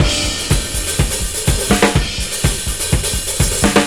• 124 Bpm Breakbeat Sample G Key.wav
Free breakbeat sample - kick tuned to the G note. Loudest frequency: 3409Hz
124-bpm-breakbeat-sample-g-key-9mS.wav